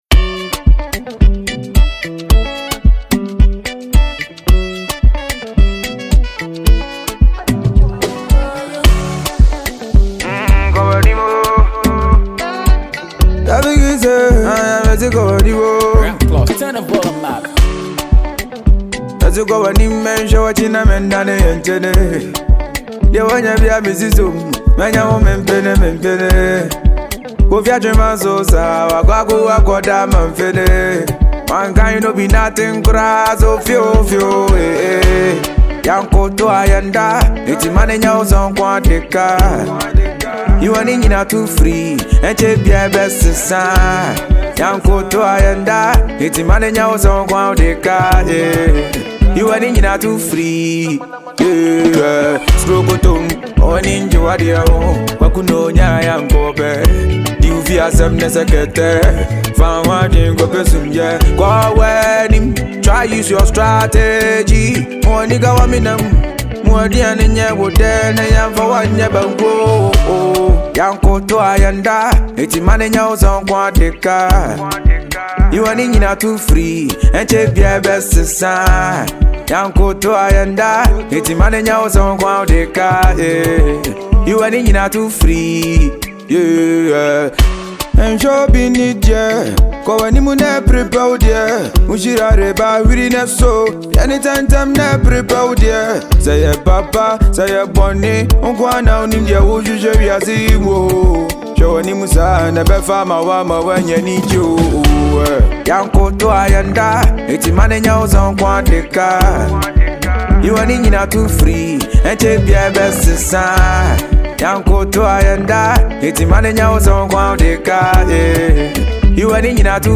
Enjoy this amazing Ghana Afrobeat.